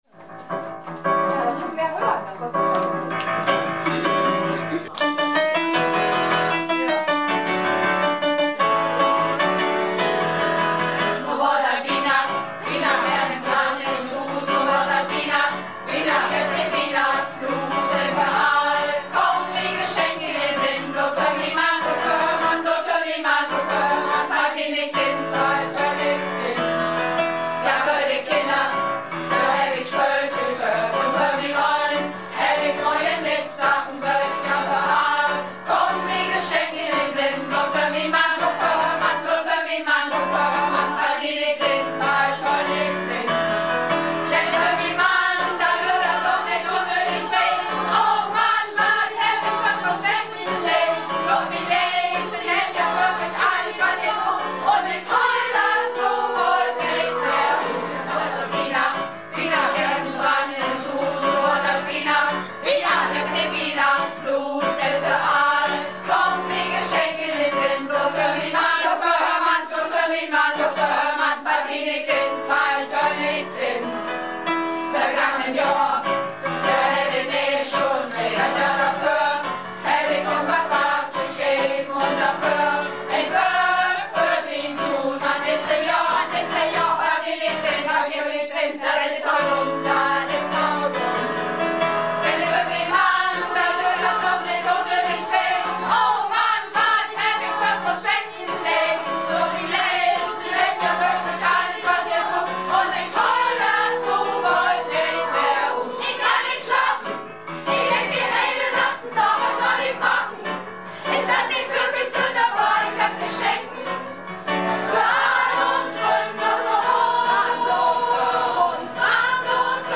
(Gesamtprobe)